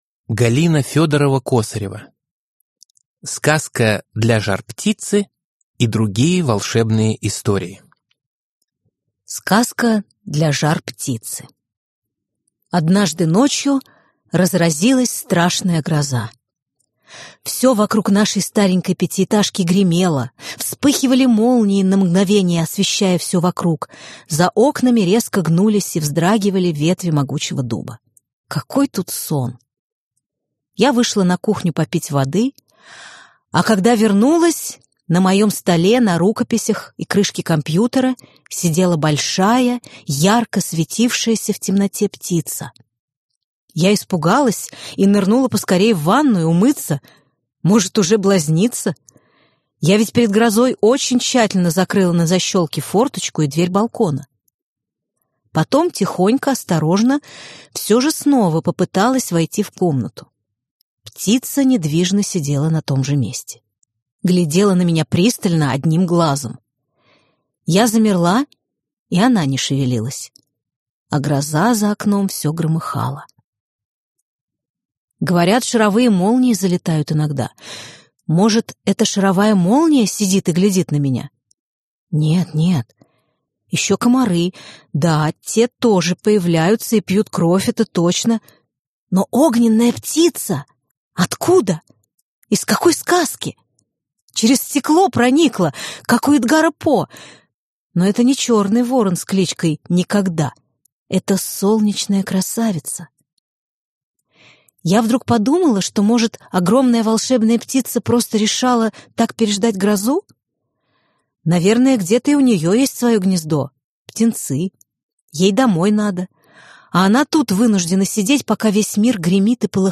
Аудиокнига Сказка для жар-птицы и другие волшебные истории | Библиотека аудиокниг